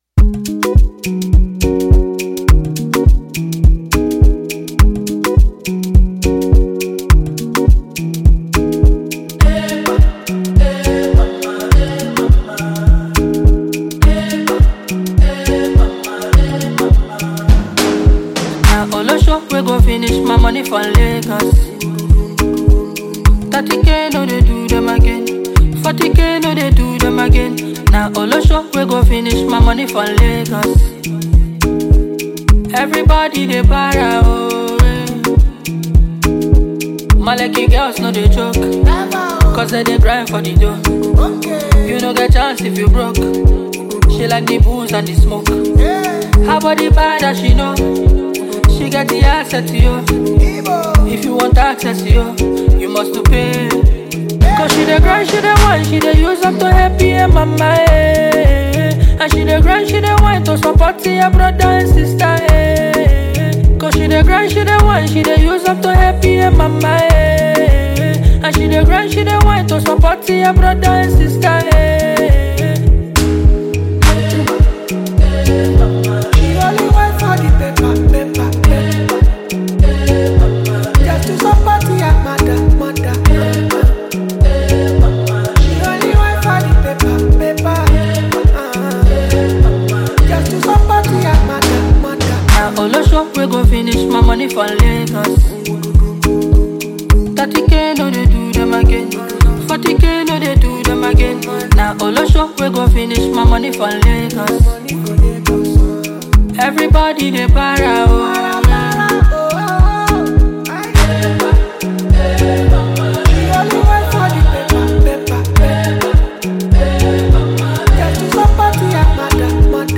rapper, and songwriter from Nigeria